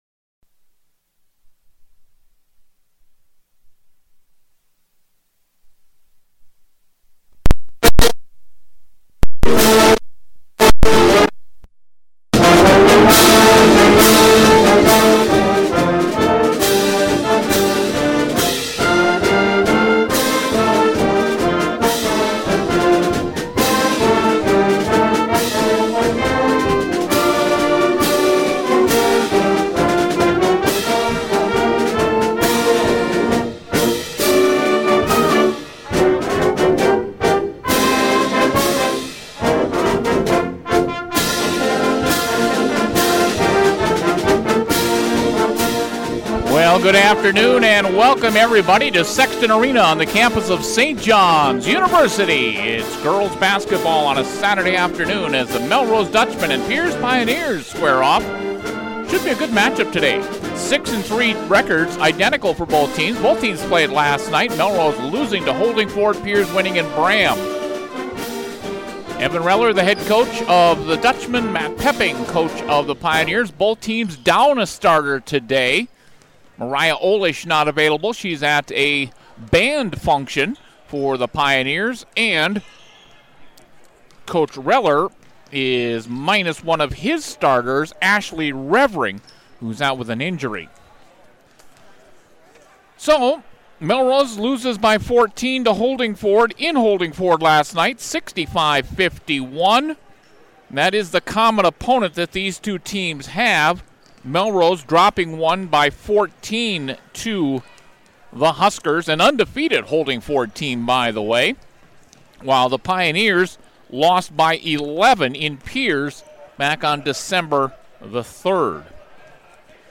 The Dutchmen hand the Pioneers a 56-45 defeat in a game played at St. John's University.